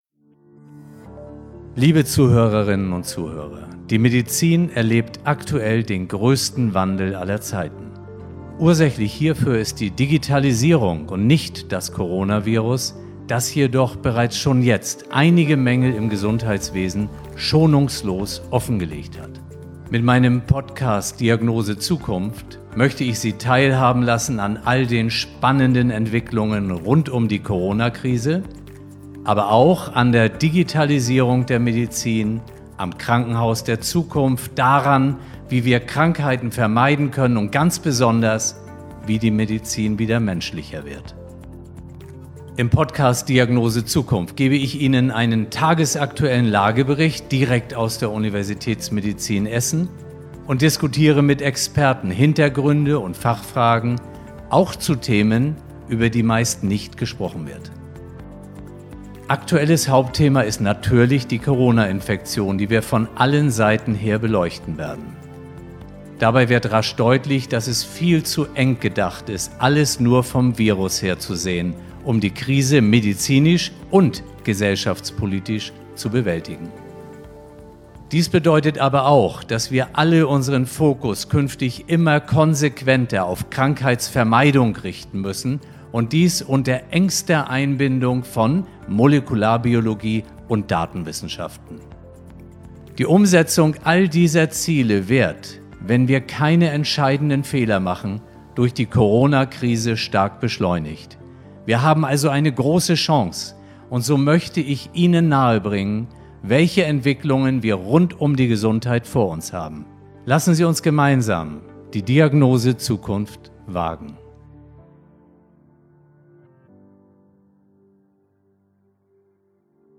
Alleine und im Interview mit